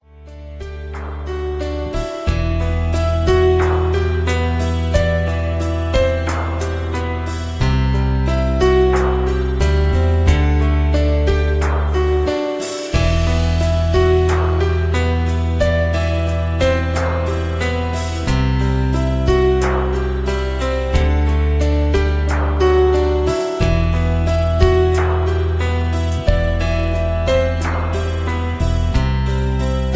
(Key-Em)
Just Plain & Simply "GREAT MUSIC" (No Lyrics).